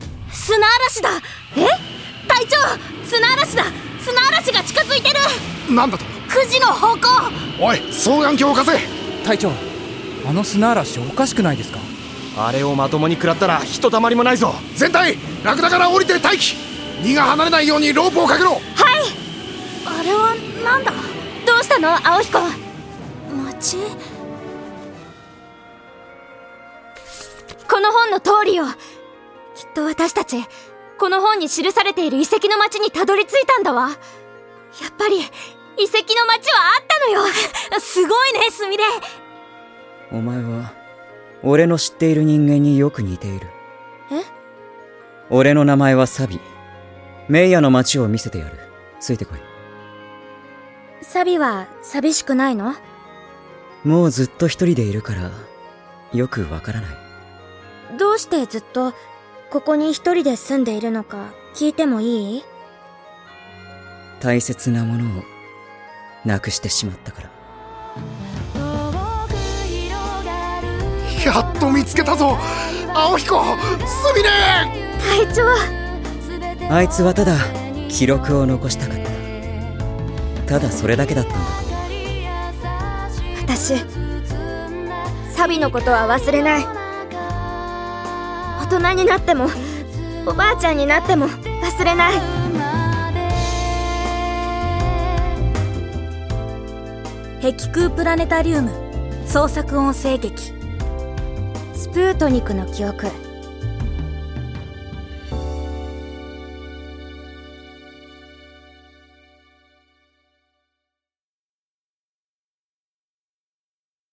碧空プラネタリウム制作の創作音声劇CD『スプートニクの記憶』の告知サイトです。